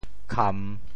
墈 Radical and Phonetic Radical 土 Total Number of Strokes 14 Number of Strokes 11 Mandarin Reading kàn TeoChew Phonetic TeoThew kam3 文 Chinese Definitions 墈kàn ⒈〈古〉险陡的堤岸。